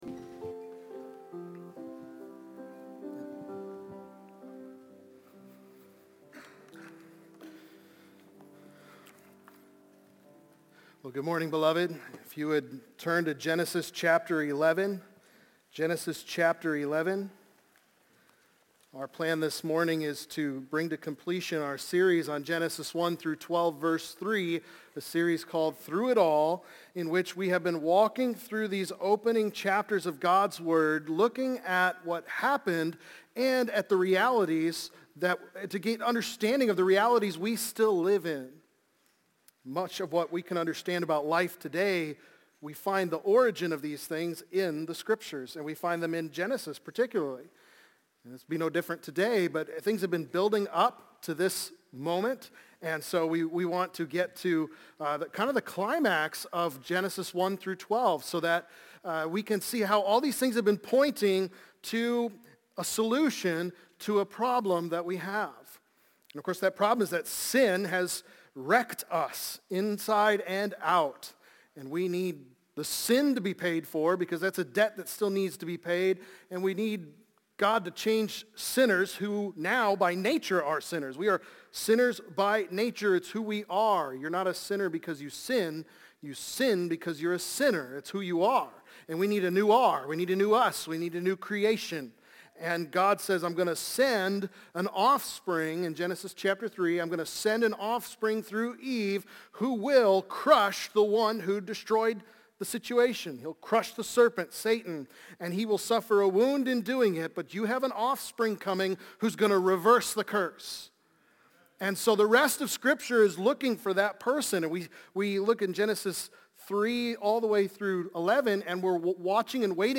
A New Beginning | Baptist Church in Jamestown, Ohio, dedicated to a spirit of unity, prayer, and spiritual growth